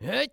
CK蓄力10.wav
CK蓄力10.wav 0:00.00 0:00.33 CK蓄力10.wav WAV · 28 KB · 單聲道 (1ch) 下载文件 本站所有音效均采用 CC0 授权 ，可免费用于商业与个人项目，无需署名。
人声采集素材/男2刺客型/CK蓄力10.wav